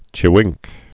(chĭ-wĭngk)